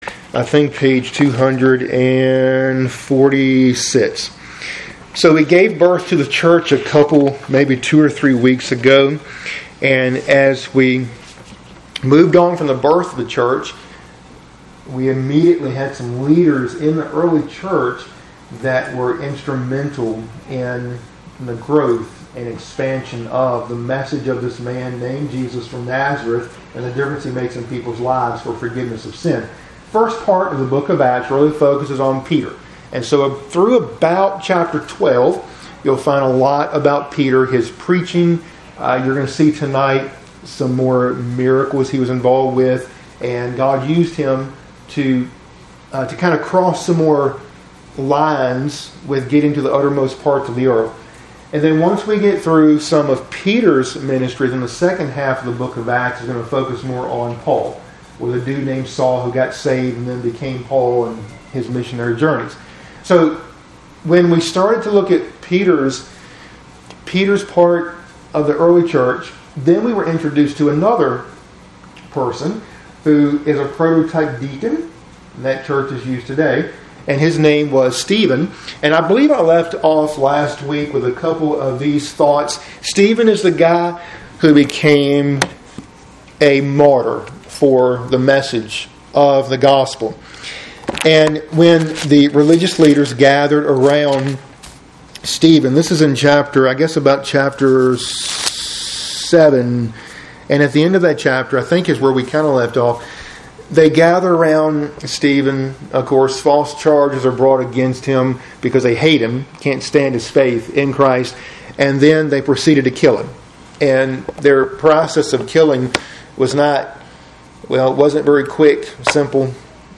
Midweek Bible Study – Lesson 50 (cont.)